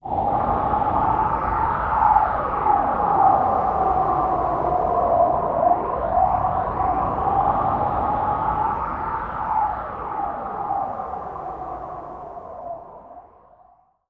Index of /90_sSampleCDs/Trance_Formation/Atmospheric
25_Haunting.WAV